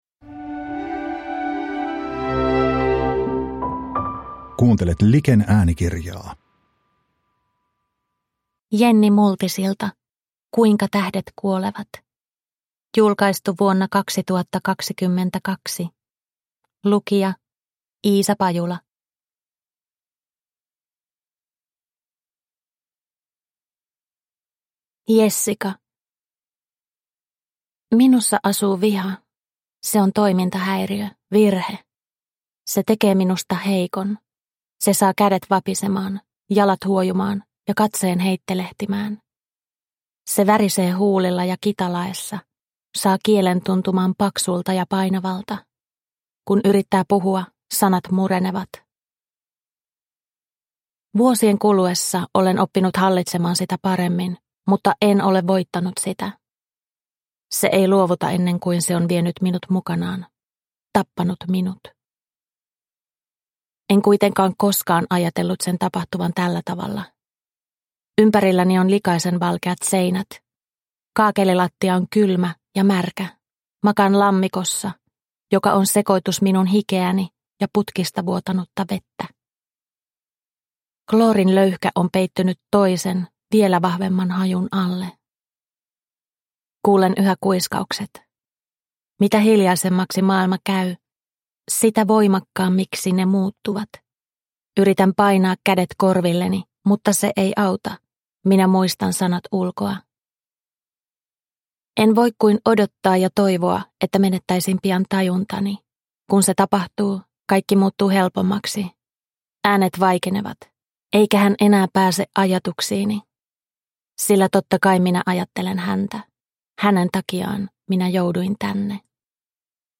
Kuinka tähdet kuolevat – Ljudbok – Laddas ner